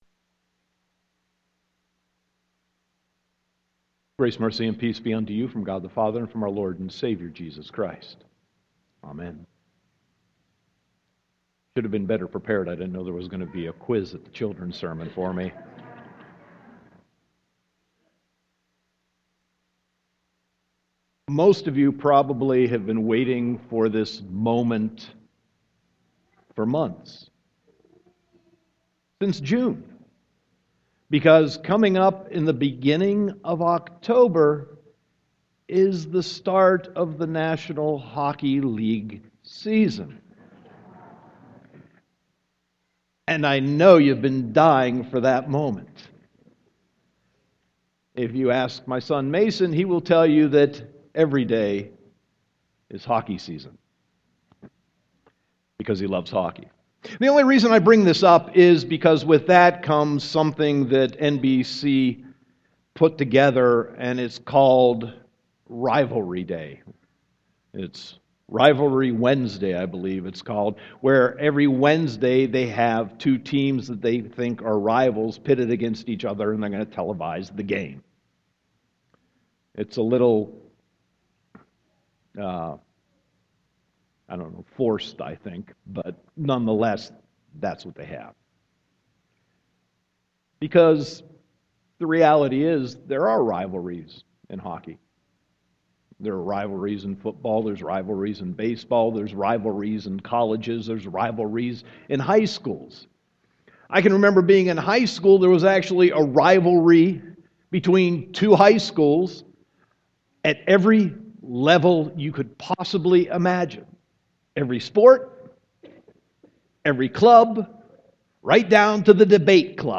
Sermon 9.27.2015